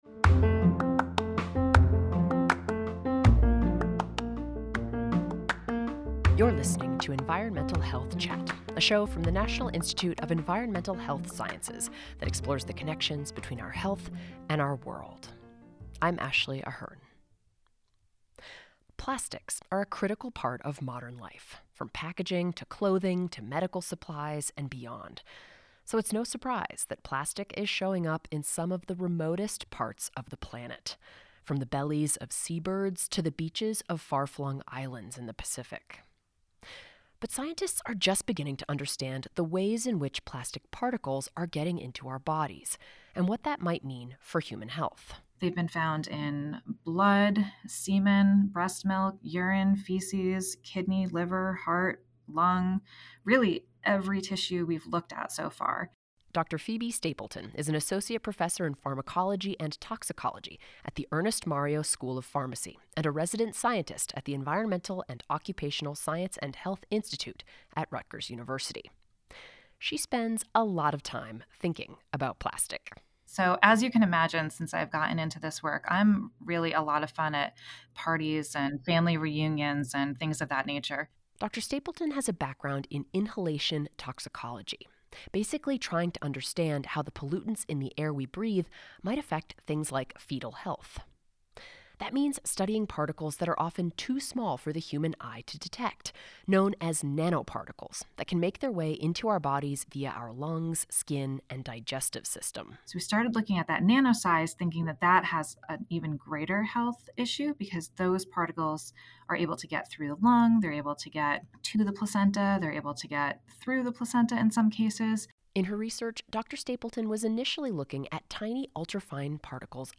Interviewee